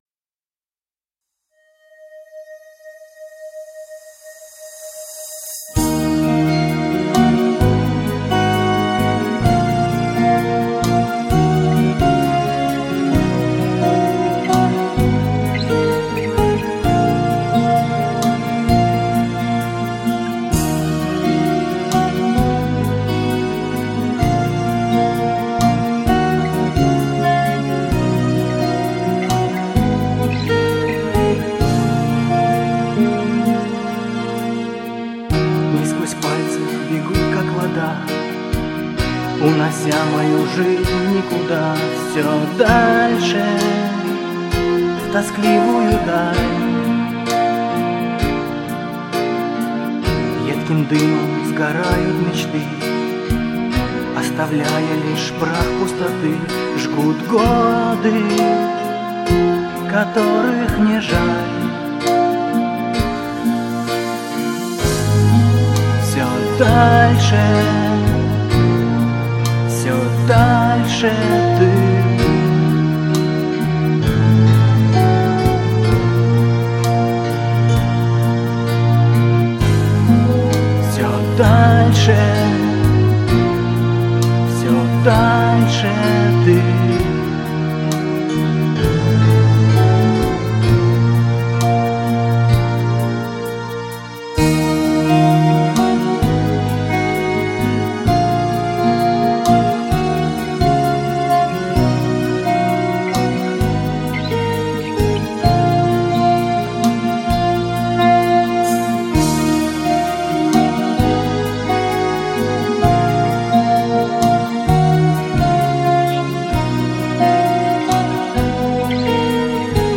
Клавиши, гитара, вокал, перкуссия